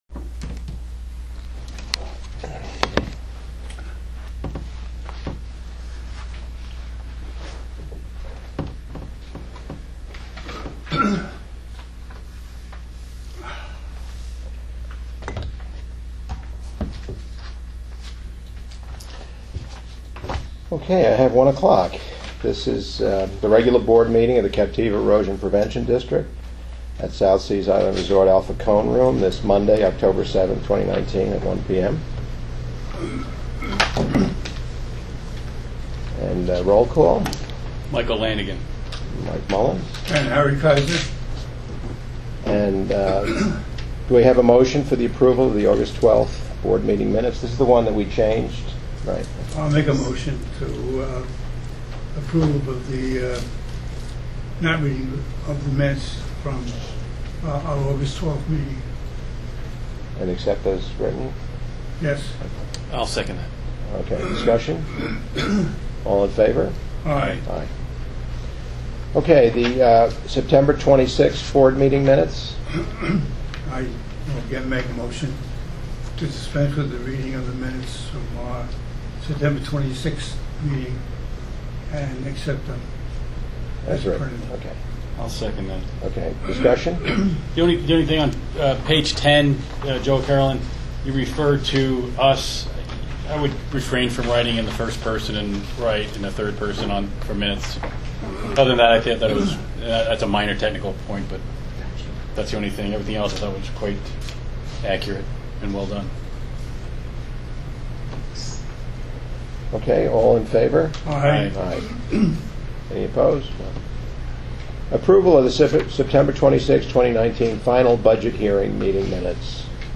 Below are a list of the publicly noticed board meetings of the the Captiva Erosion Prevention District.
Board Meeting October 2019